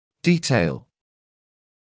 [‘diːteɪl][‘диːтэйл]деталь, подробность